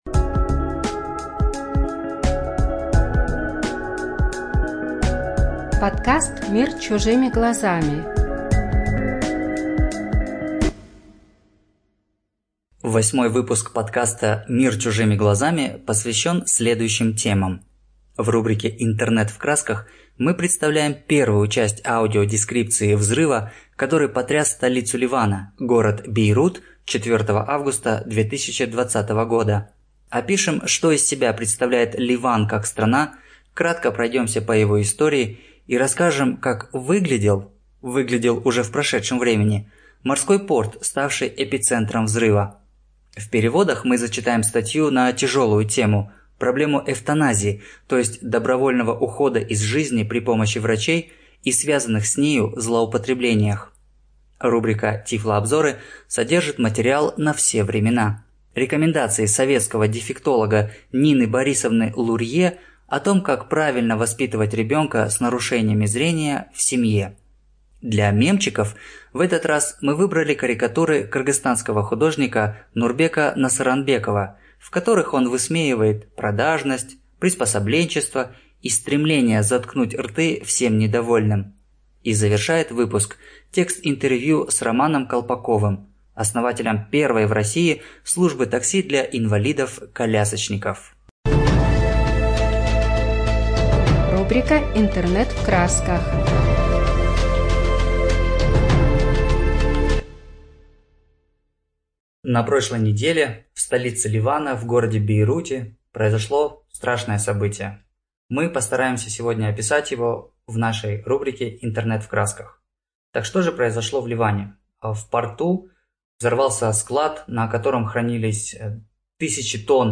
Студия звукозаписиEsic